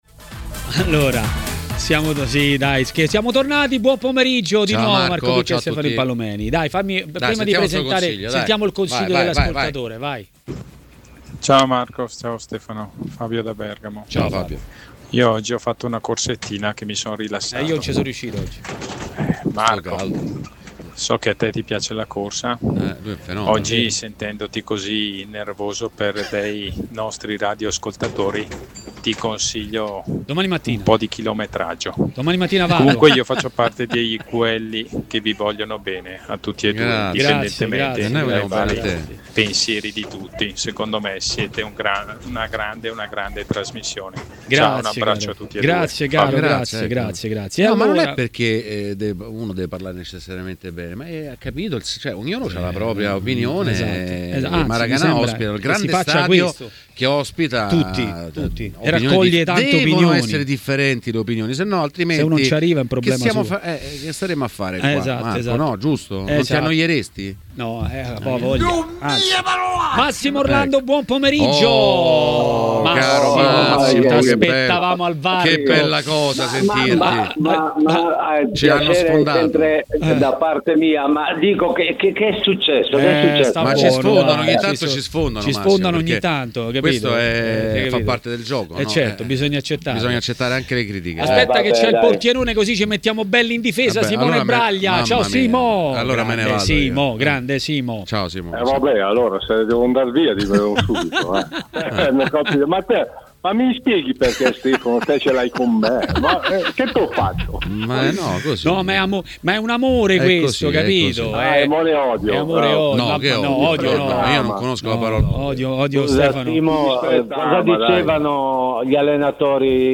è intervenuto a TMW Radio, durante Maracanà.